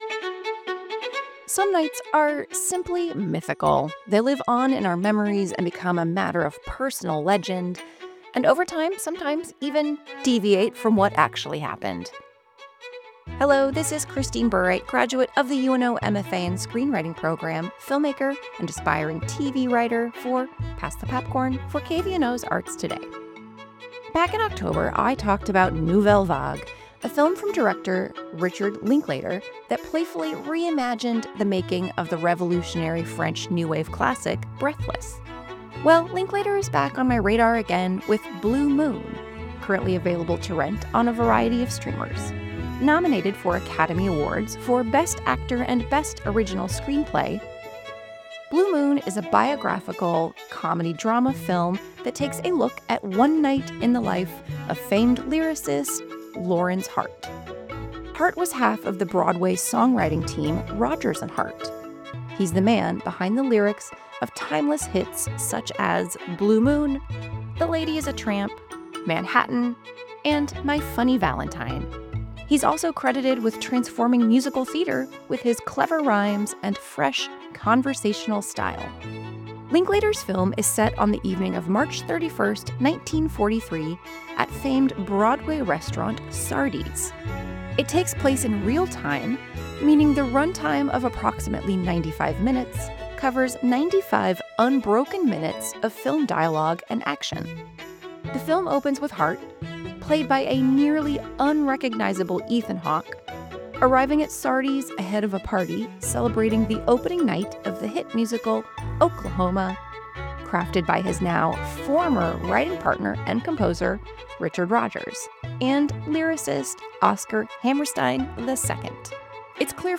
As part of the weekly Pass the Popcorn segment on Arts Today, the feature invites audiences to discover films that bring history to life and deepen our understanding of the artists behind the music.